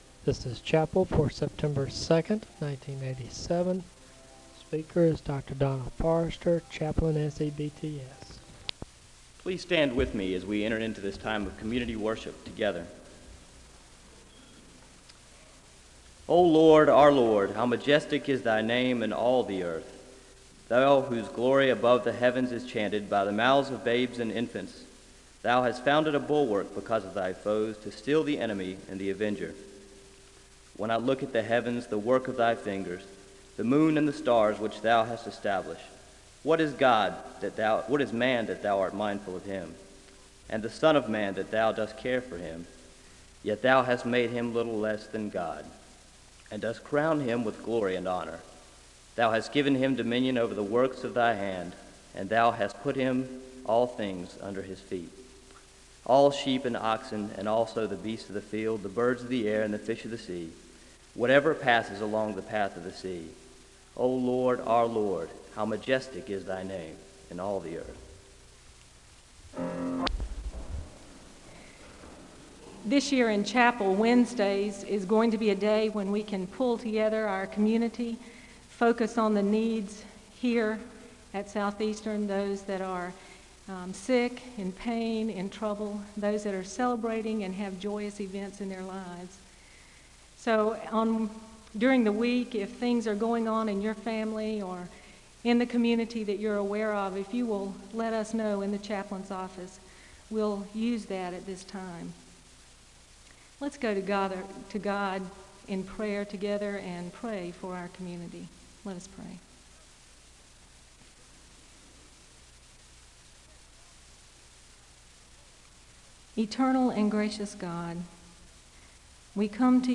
The service begins with a call to worship (0:00-1:15). Prayer concerns are shared with the congregation and there is a moment of prayer (1:16-3:19). There are Scripture readings from Genesis 33 and Hebrews 4 (3:20-6:11). The choir sings a song of worship (6:12-9:40).